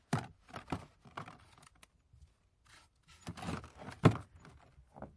在瑞典的森林里 " 冬天站在门廊上
描述：站在/走在我满是雪的木制门廊上
Tag: 门廊 靴子 足迹